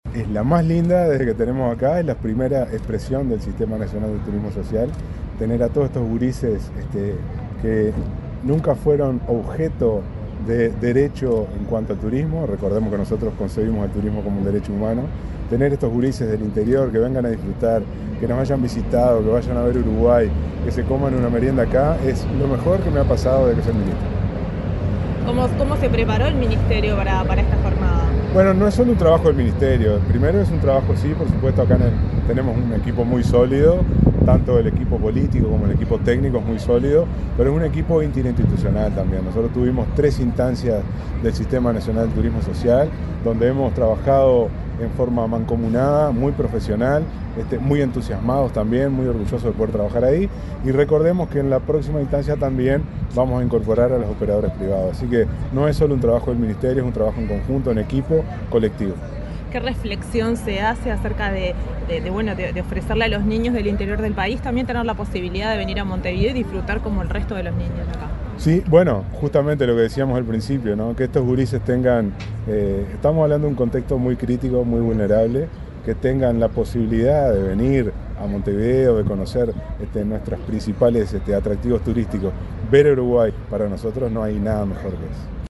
Declaraciones del ministro de Turismo, Pablo Menoni